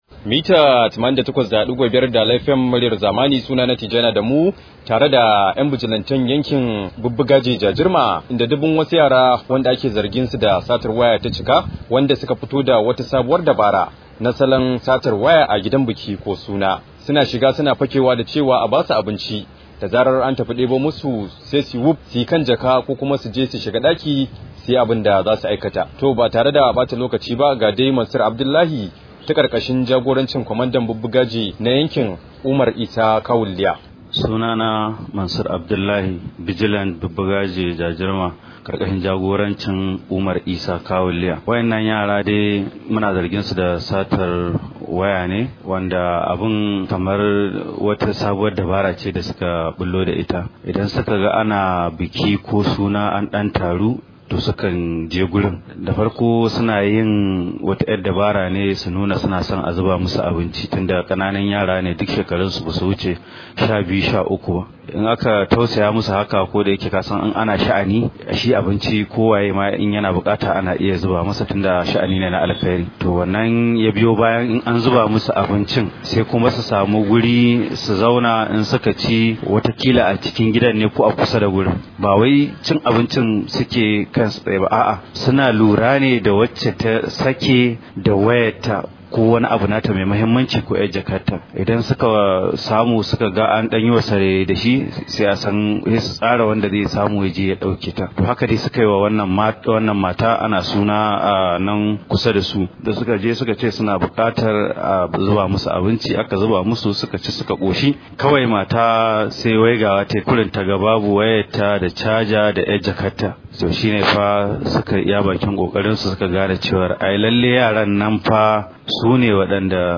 Rahoto: Mun kama yara da a ke zargi da kwacen waya da sata – Bijilante